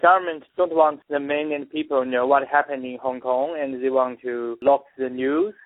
THIS MAN IN CHINA SAYS THE COMMUNIST CHINESE GOVERNMENT BLOCKS THE INTERNET TO KEEP PEOPLE FROM KNOWING WHAT IS GOING ON IN HONG KONG.